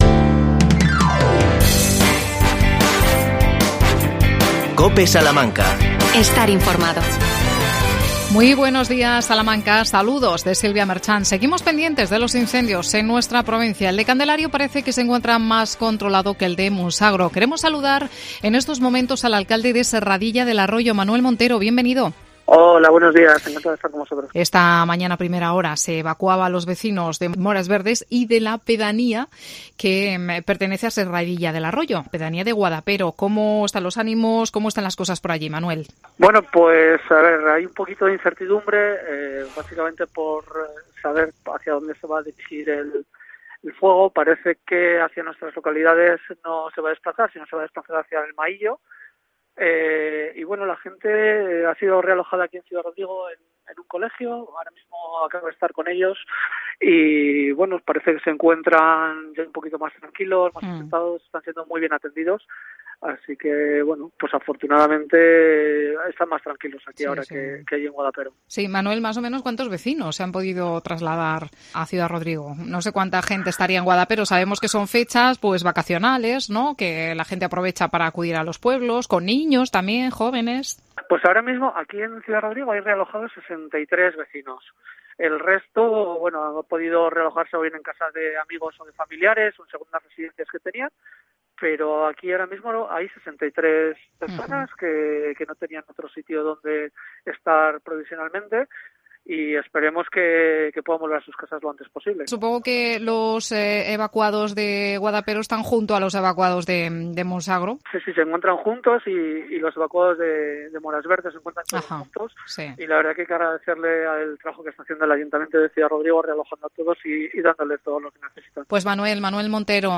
Entrevista a Miguel Moreno, jefe provincial de tráfico en Salamanca.